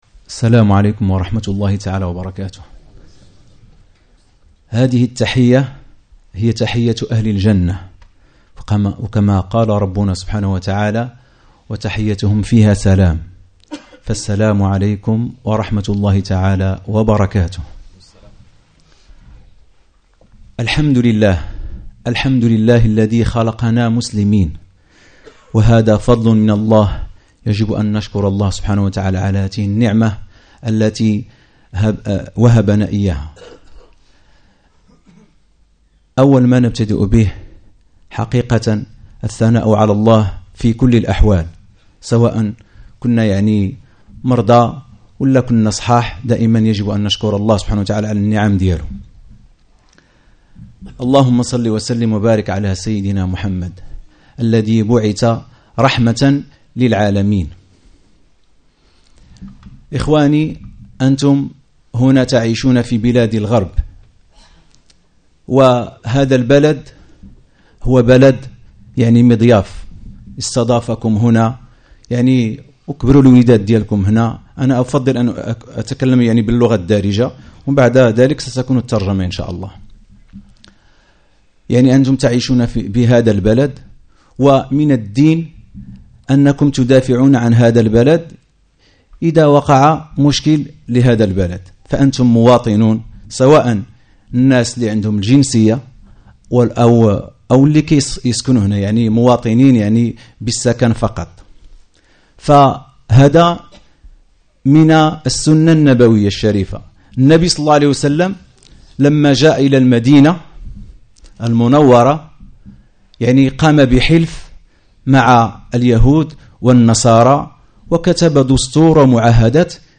Discours du 18 mai 2012